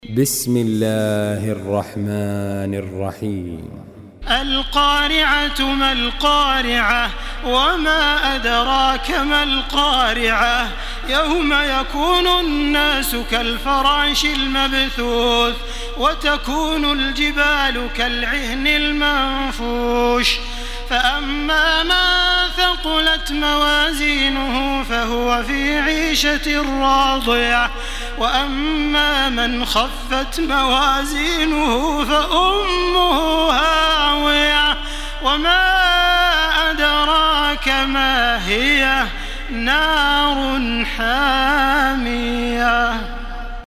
Makkah Taraweeh 1434
Murattal